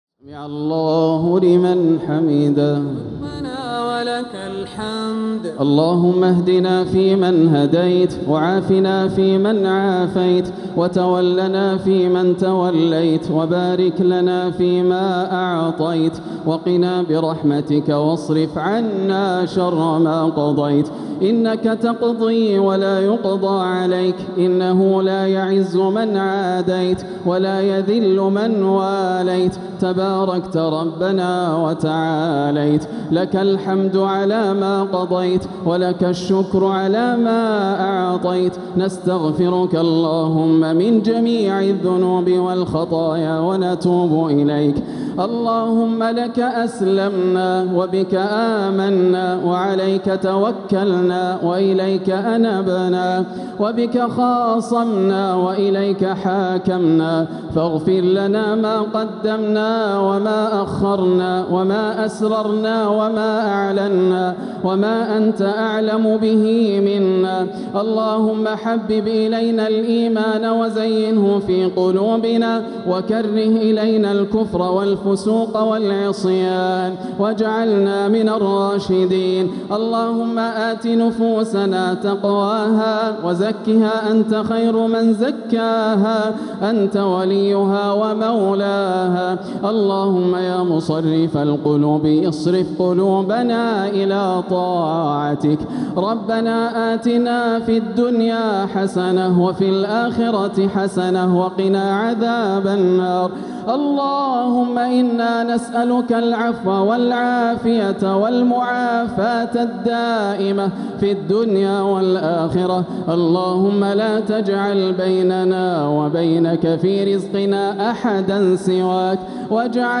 دعاء القنوت ليلة 12 رمضان 1447هـ | Dua 12th night Ramadan 1447H > تراويح الحرم المكي عام 1447 🕋 > التراويح - تلاوات الحرمين